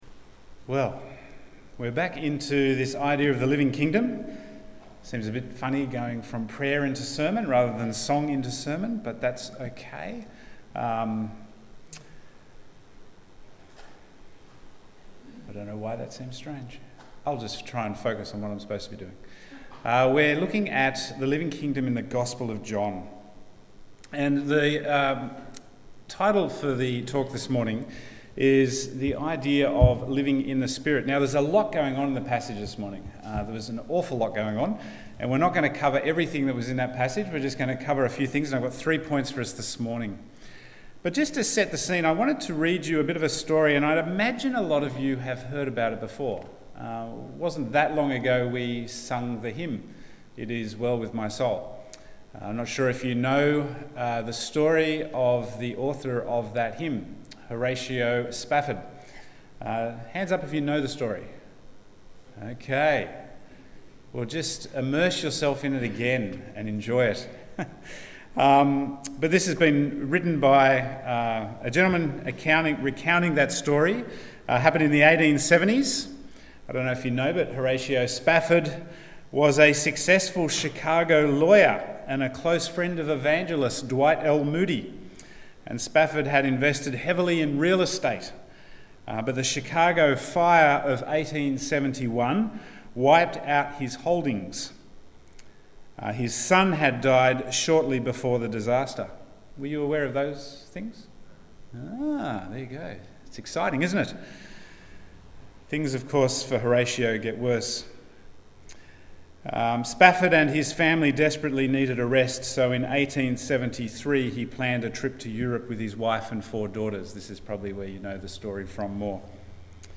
Bible Text: John 16:5-16 | Preacher